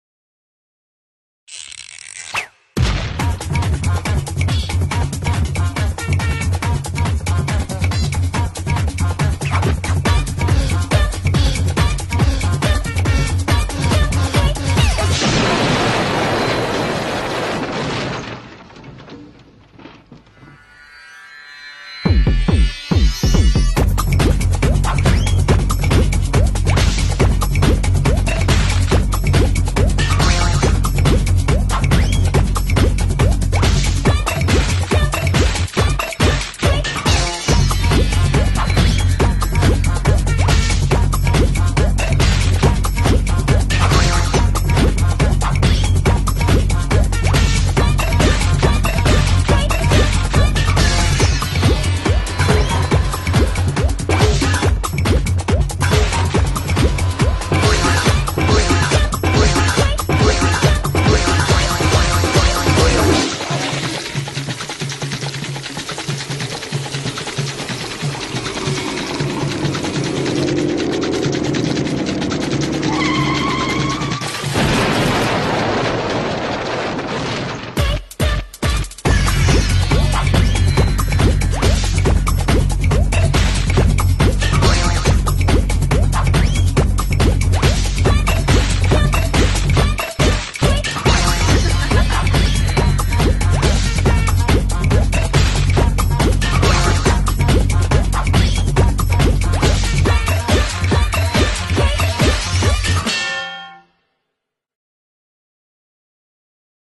BPM140
Audio QualityMusic Cut